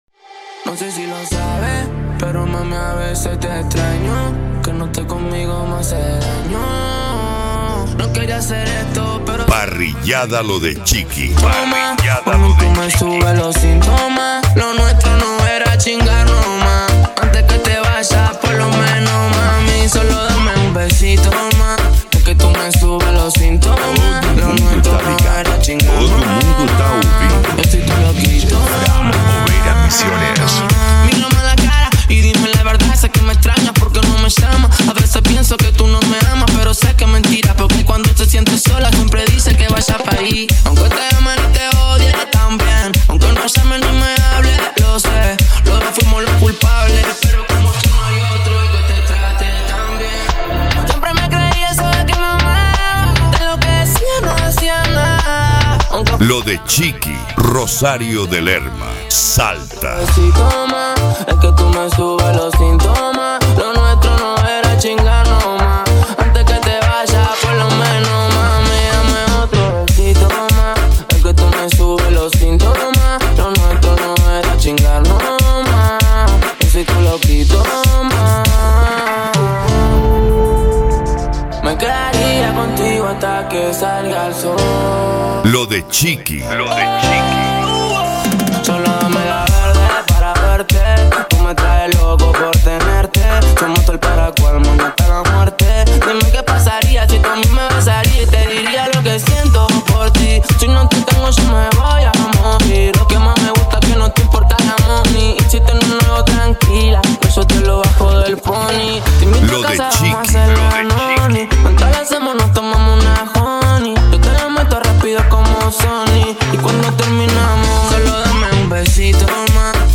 Remix
Retro Music